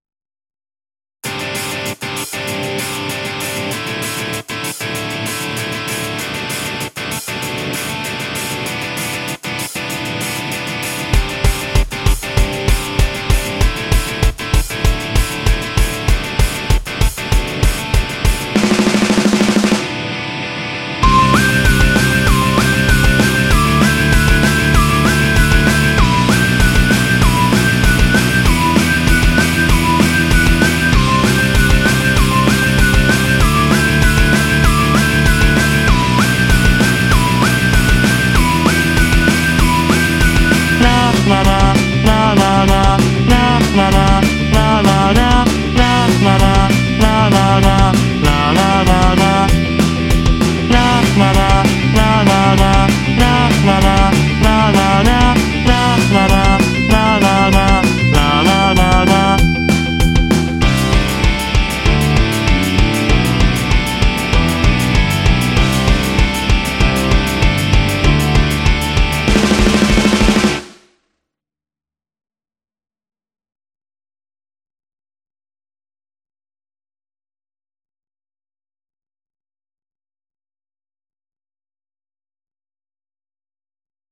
爽やかロック.mp3